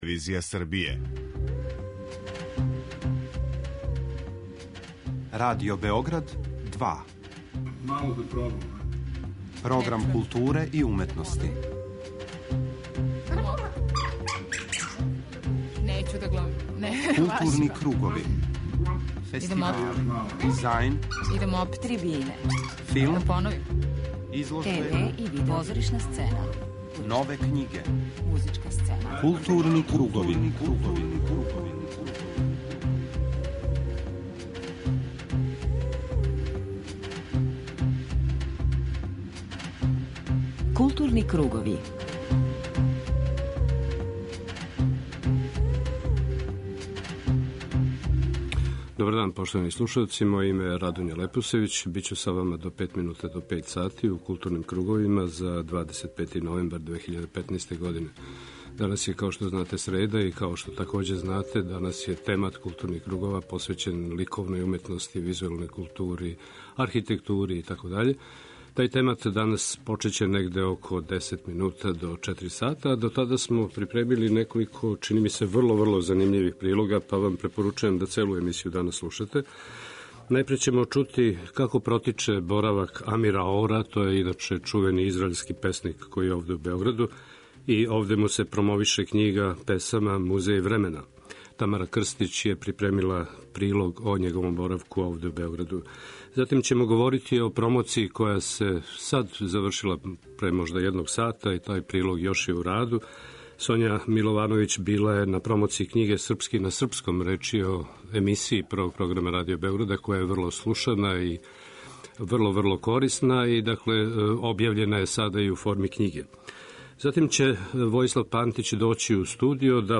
преузми : 41.26 MB Културни кругови Autor: Група аутора Централна културно-уметничка емисија Радио Београда 2.